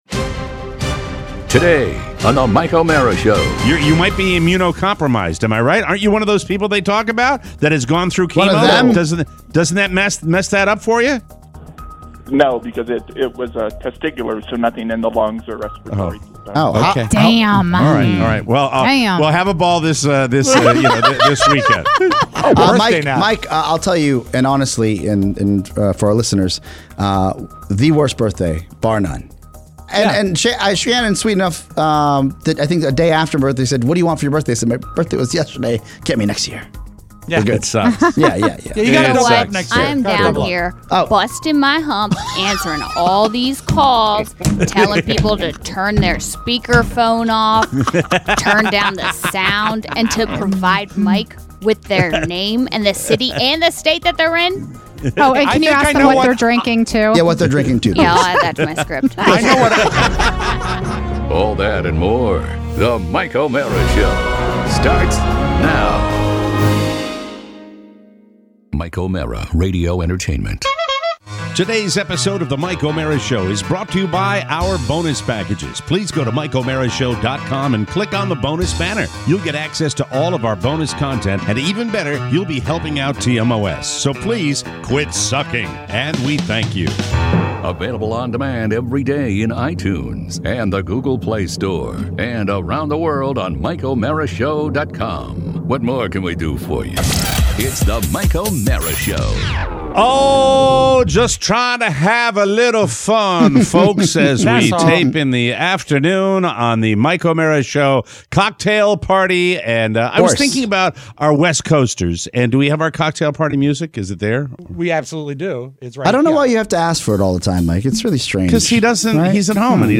It’s Cocktail Party time and we’re taking your calls! We have cooking advice… drinking advice… and Centreville is in the house.